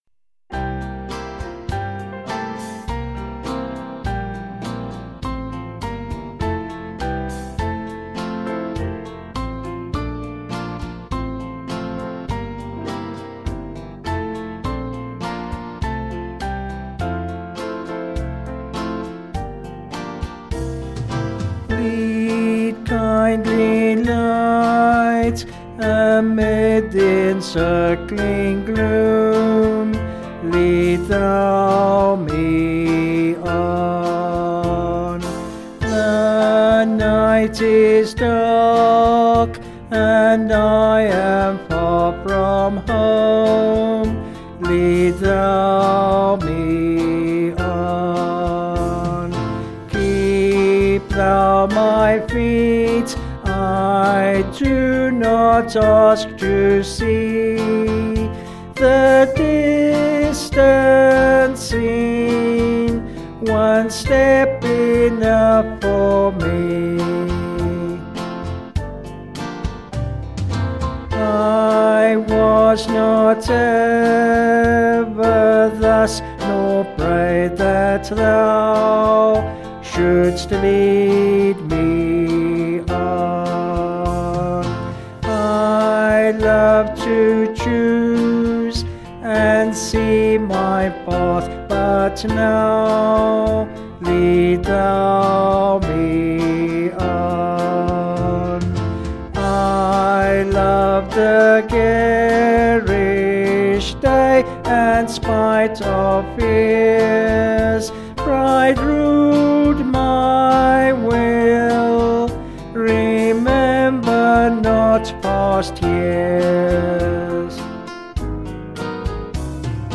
Vocals and Band   263.8kb Sung Lyrics